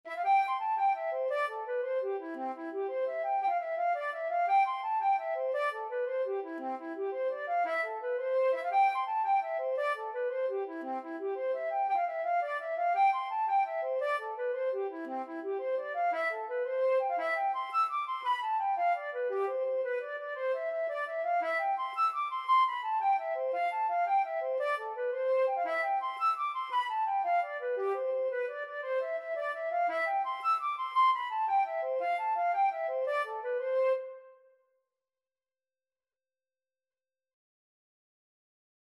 Traditional Trad. Father Tom's Wager (Irish Folk Song) Flute version
C major (Sounding Pitch) (View more C major Music for Flute )
6/8 (View more 6/8 Music)
C5-E7
Flute  (View more Intermediate Flute Music)
Traditional (View more Traditional Flute Music)